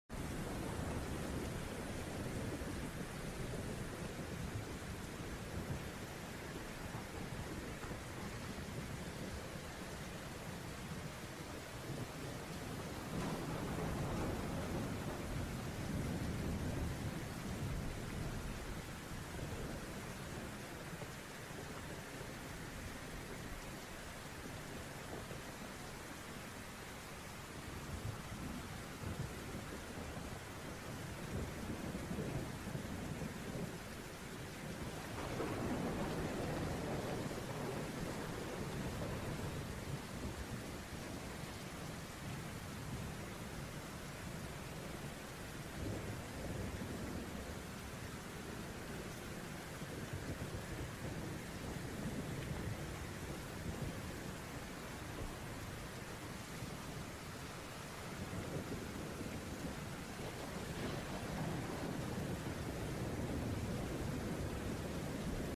A peaceful mountain lake in sound effects free download
A peaceful mountain lake in Austria, wrapped in mist and rain.
Let the soft rain and alpine silence guide you into deep rest and calm reflections. Perfect for winding down and finding inner peace.